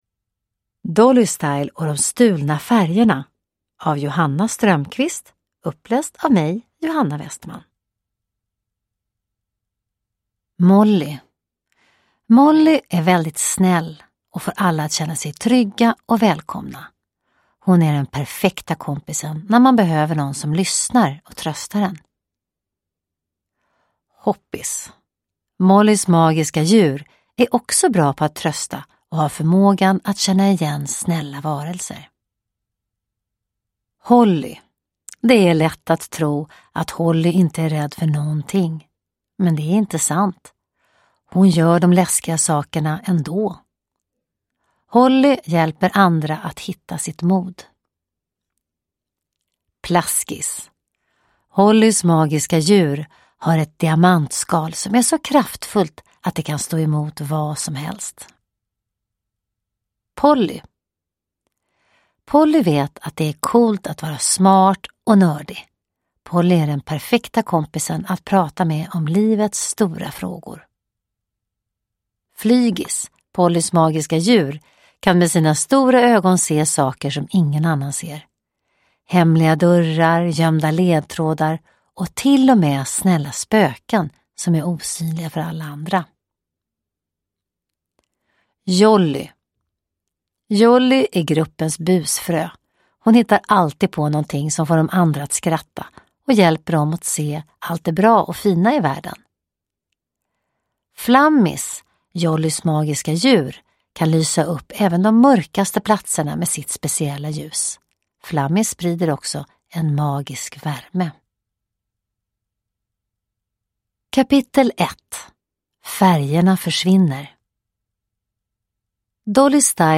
Dolly Style och de stulna färgerna – Ljudbok
Uppläsare: Johanna Westman